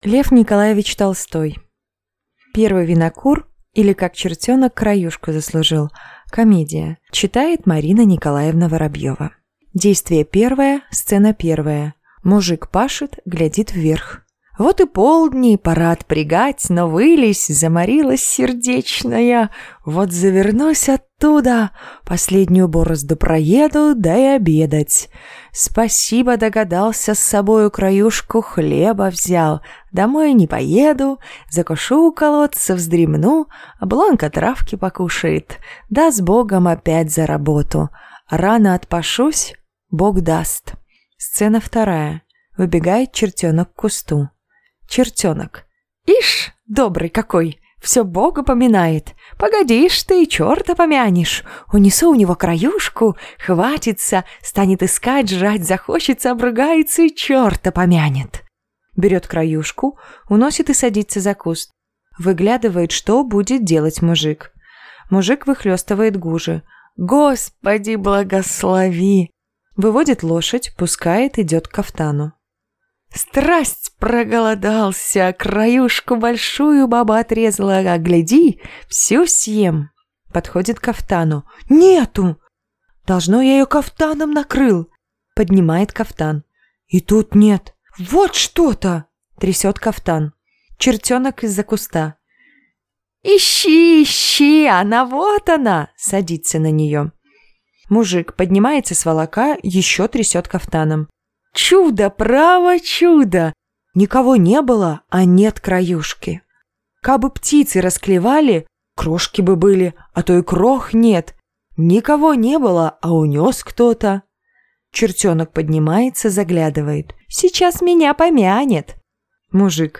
Аудиокнига Первый винокур, или Как чертенок краюшку заслужил | Библиотека аудиокниг